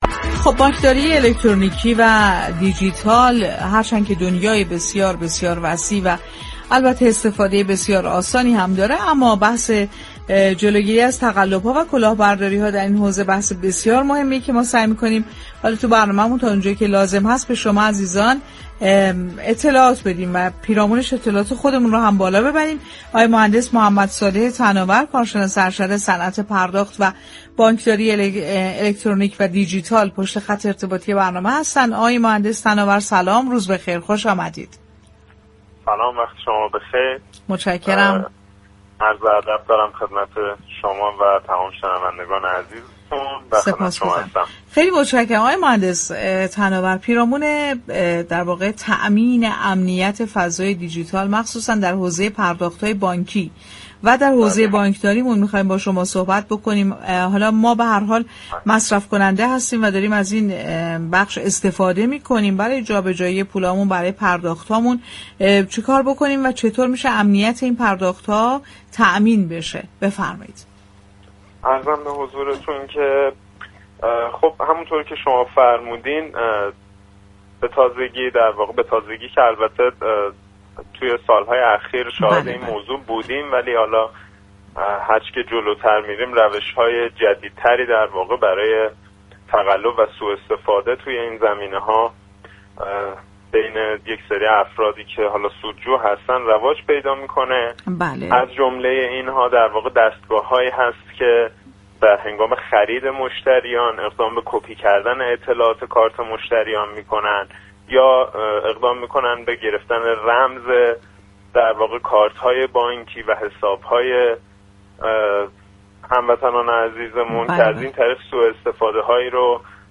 در گفت‌وگوی رادیو تهران